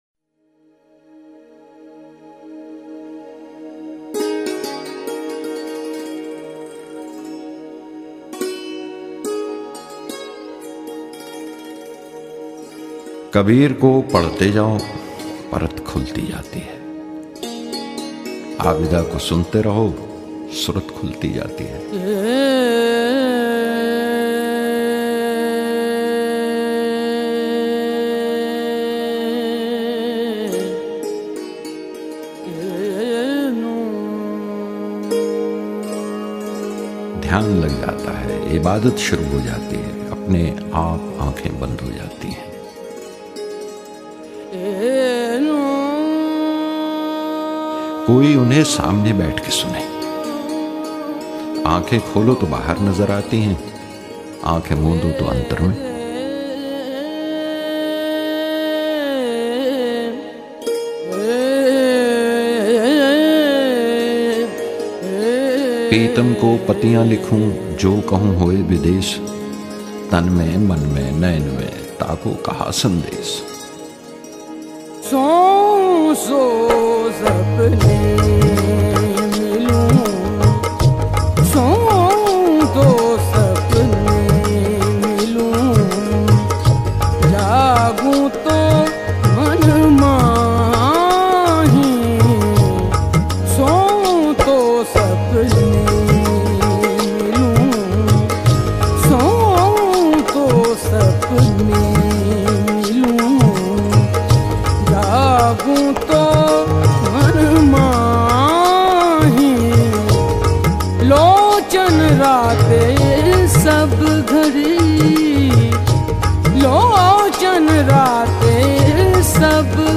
Sufi Songs